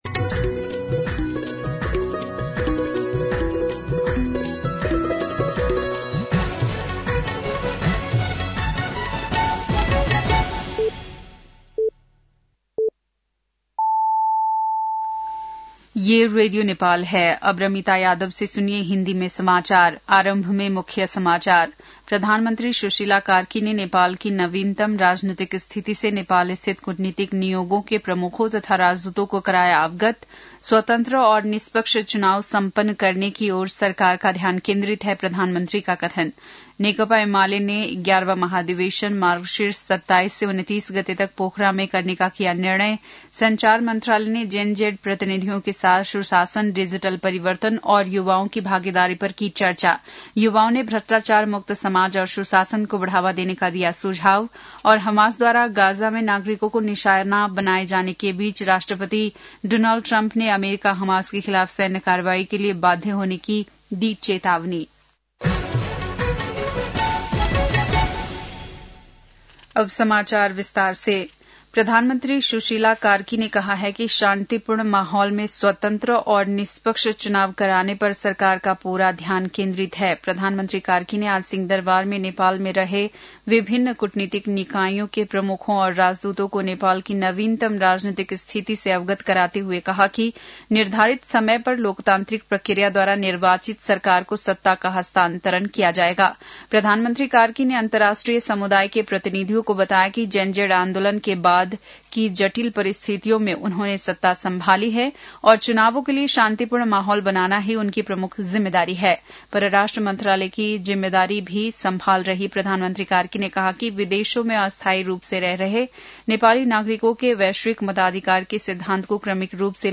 बेलुकी १० बजेको हिन्दी समाचार : ३१ असोज , २०८२
10-pm-hindi-news-6-31.mp3